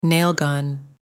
Nail gun | 7,578 of 14,400